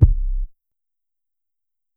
808 (Borrowed Love).wav